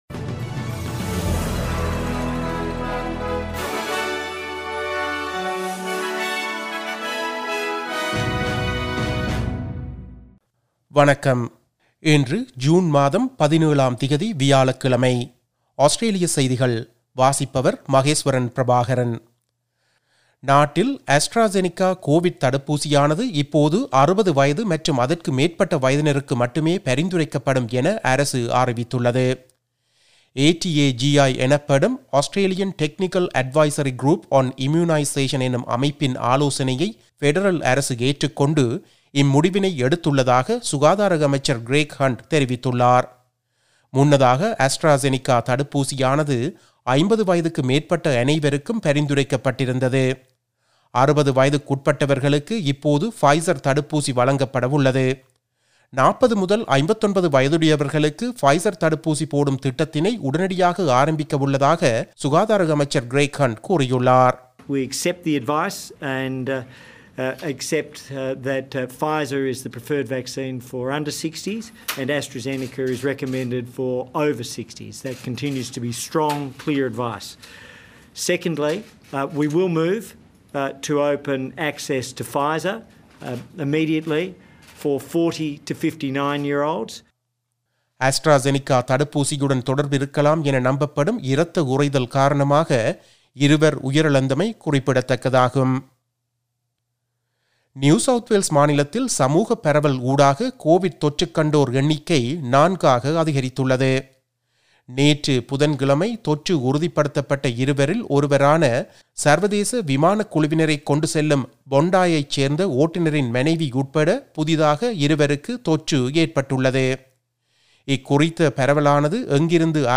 Australian news bulletin for Thursday 17 June 2021.